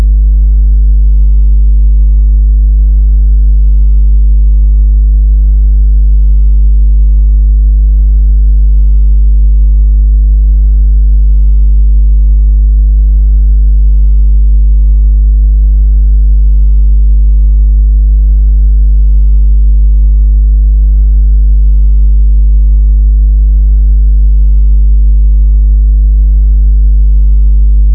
语音增强功能 悍马组12 " 噪声
描述：电源嗡嗡声
Tag: 增强 语音 噪音